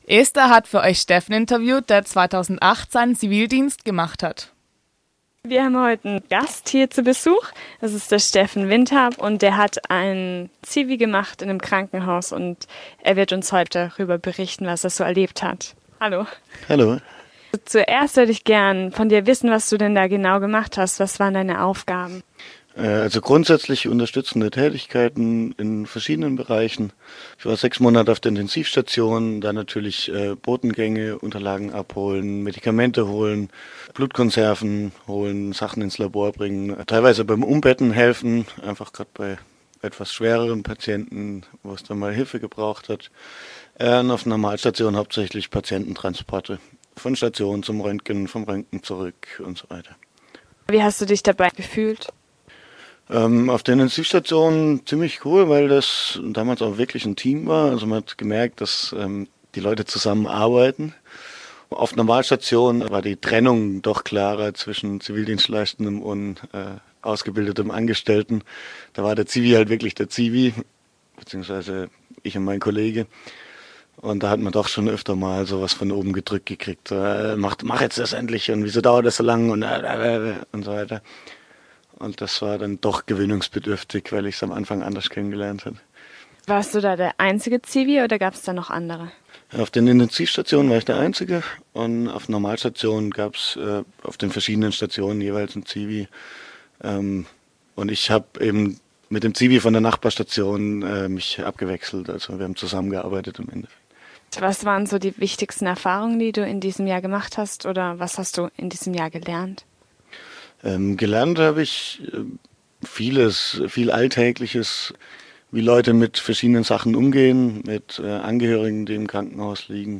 Dieser Eintrag wurde veröffentlicht unter Interview Arbeitswelt Podcast-Archiv der PH-Freiburg und verschlagwortet mit deutsch am von